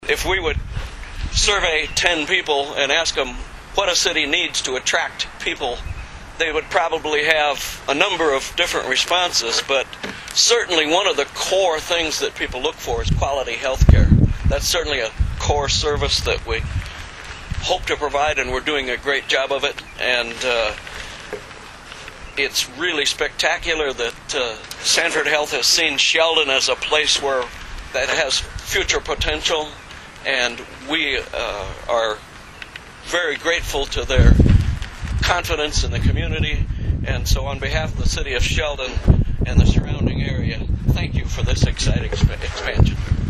Those in attendance braved the cold wind to participate in the event which, according to Sanford officials, has been seven years in the making.
Sheldon City Councilman Pete Hamill spoke to the group on behalf of the City of Sheldon.